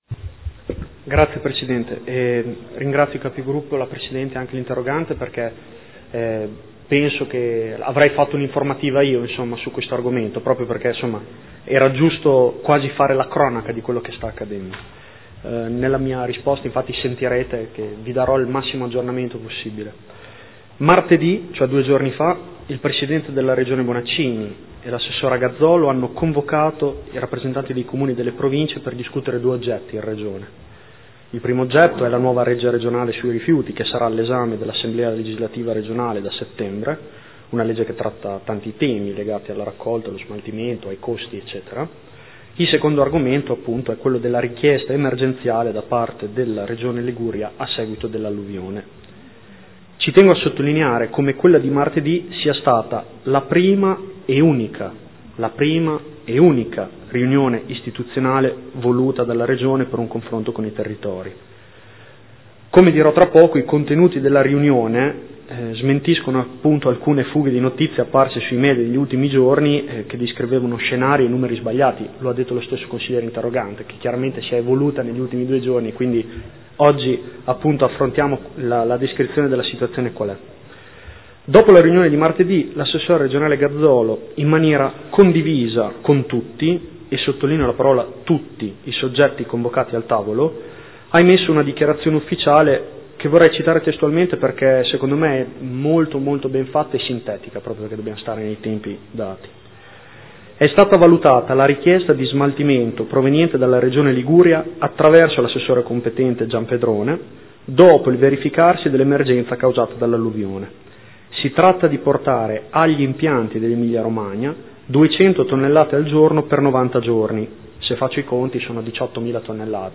Seduta del 23/07/2015 Risponde. Interrogazione: Rifiuti provenienti dalla Liguria a Modena?